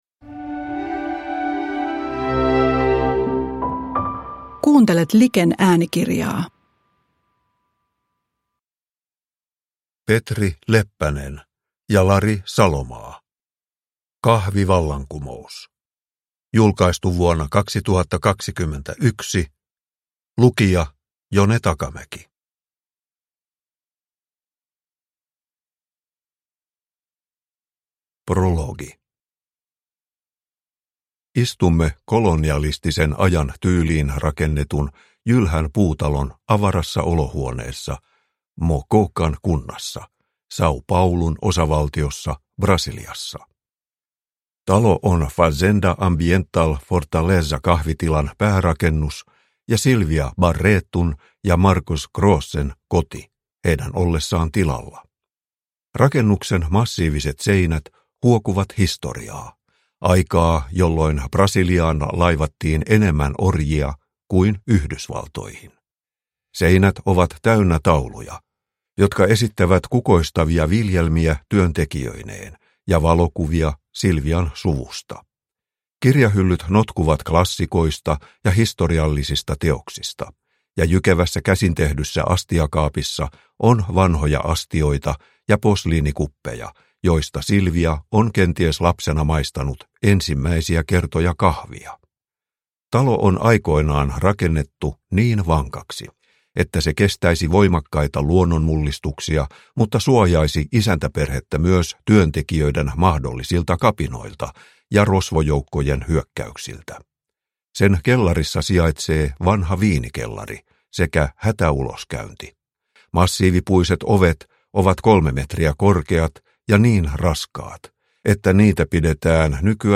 Kahvivallankumous – Ljudbok – Laddas ner